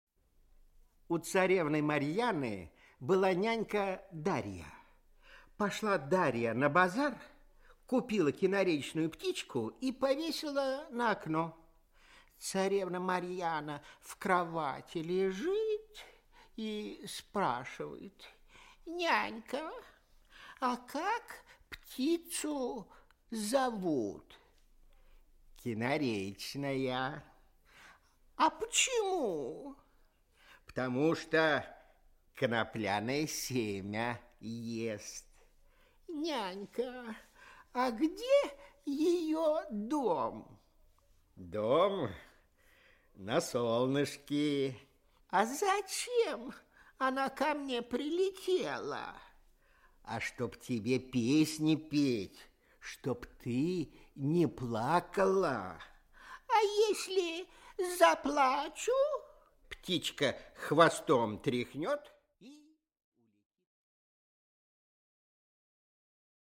Аудиокнига Жар-птица | Библиотека аудиокниг
Aудиокнига Жар-птица Автор Народное творчество Читает аудиокнигу Николай Литвинов.